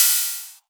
Index of /VEE/VEE2 Cymbals/VEE2 Rides
VEE2 Ride 09.wav